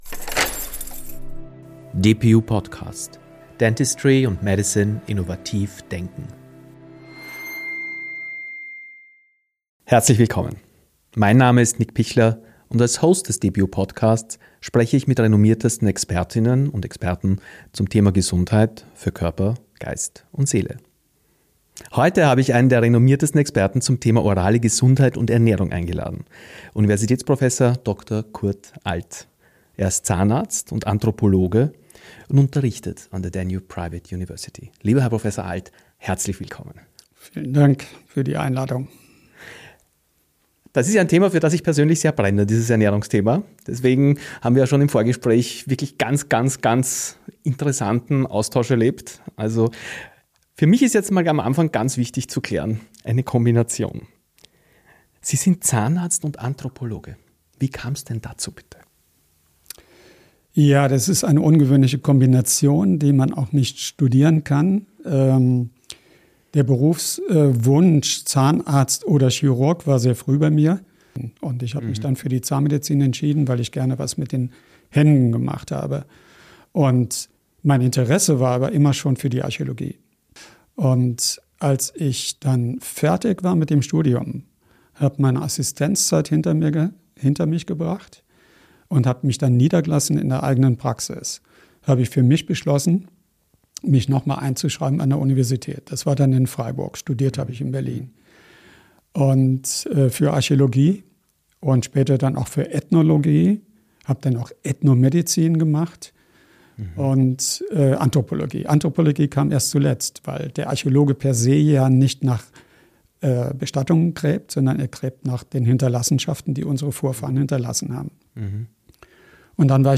Ein Gespräch über die Entstehung von Krankheiten, die Gefahren moderner Ernährungstrends, die Rolle von Milch, Fleisch und Zucker – und darüber, wie wir durch kleine, bewusste Schritte wieder gesünder leben können.